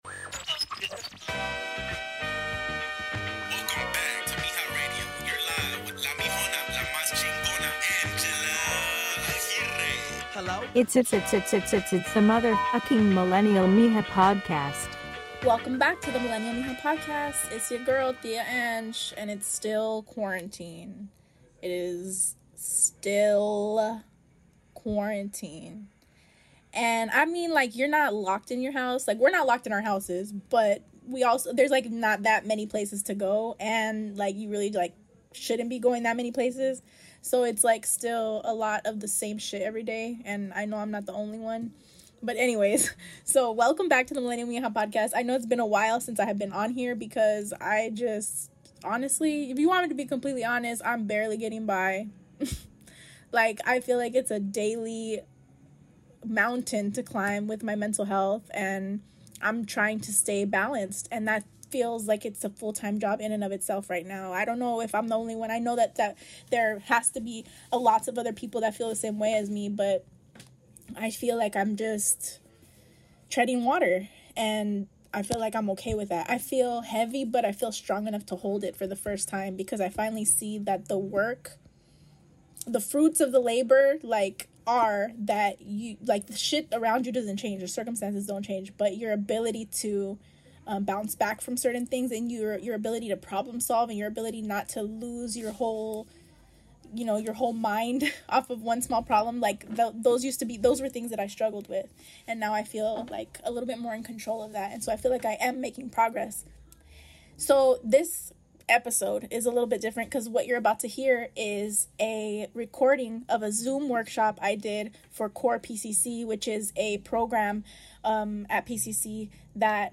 Turning Pain Into Power: A Workshop